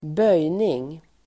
Uttal: [²b'öj:ning]